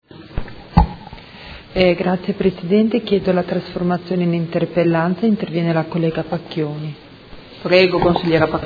Seduta del 01/06/2017. Chiede trasformazione in interpellanza su interrogazione del Gruppo Movimento cinque Stelle avente per oggetto: Come procede l’unificazione “sperimentale” degli Ospedali del Comune di Modena?